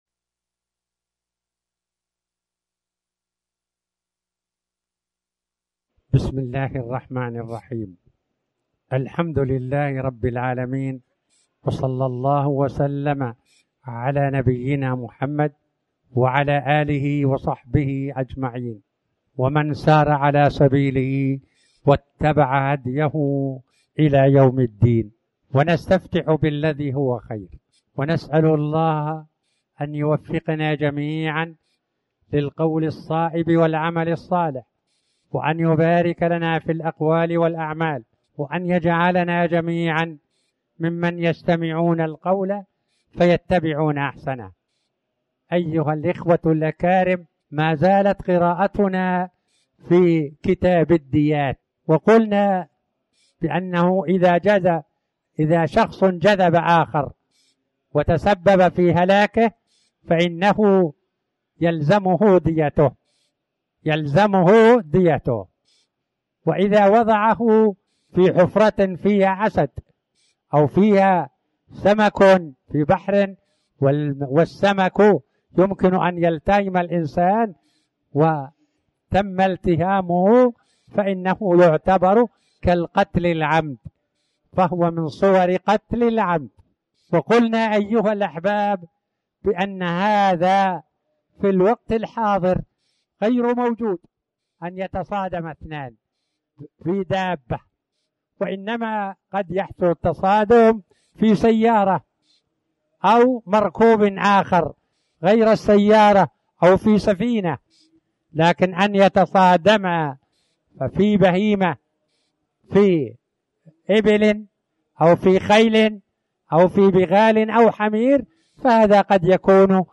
تاريخ النشر ٢٩ شعبان ١٤٣٩ هـ المكان: المسجد الحرام الشيخ